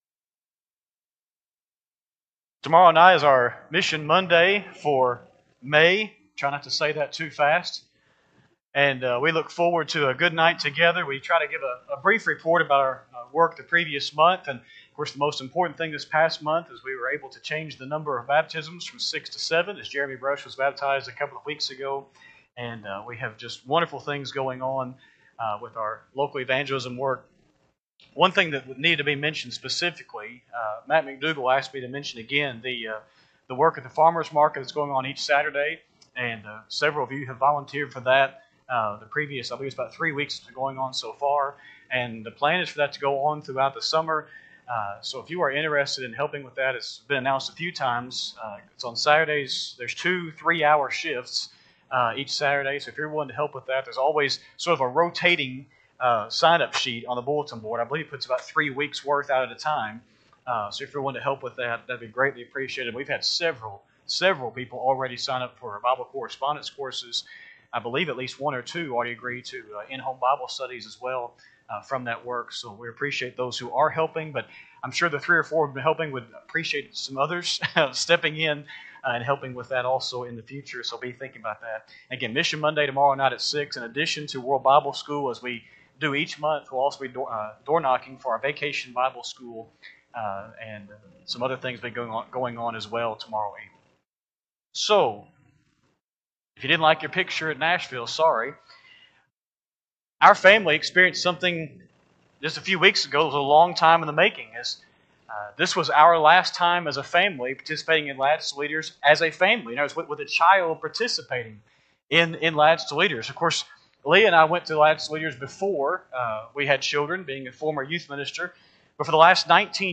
5-11-25-Sunday-PM-Sermon.mp3